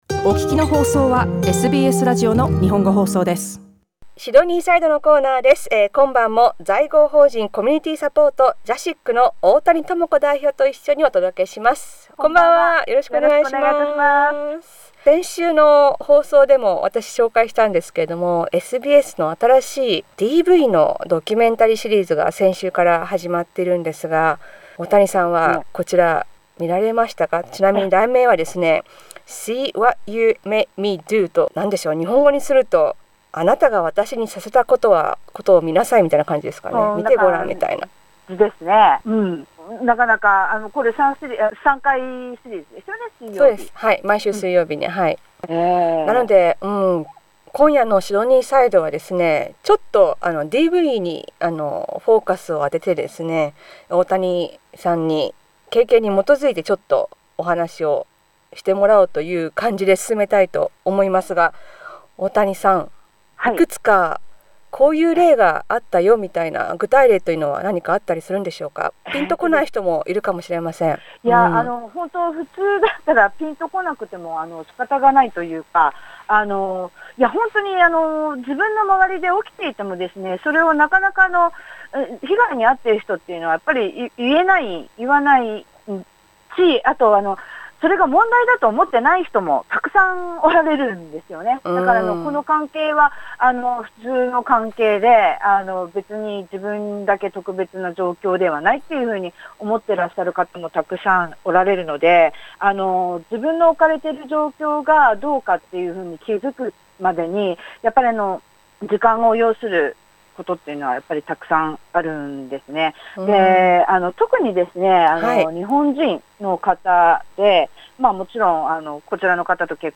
Sydneyside is our weekly radio segment focusing on events and information beneficial for the Japanese community. The theme for this week-- domestic violence in the community.